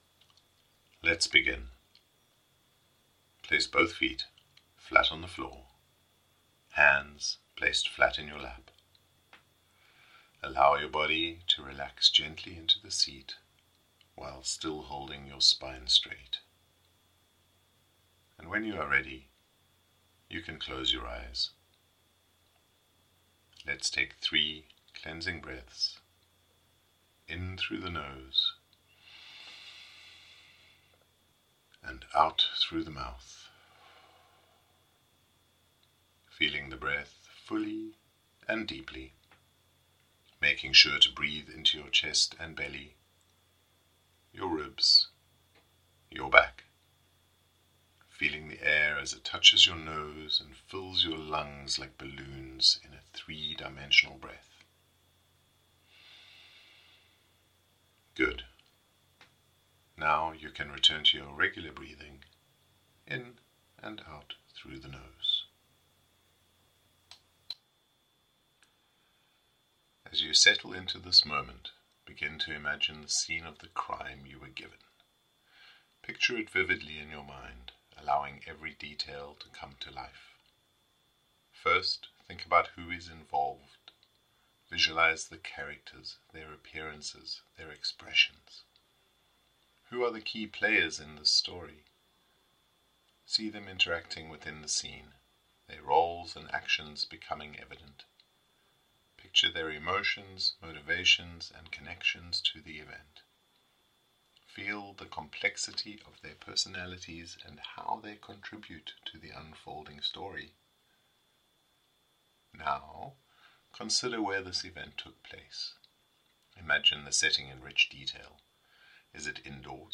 Workshop Meditation
LL02-Meditation-crime-scene.mp3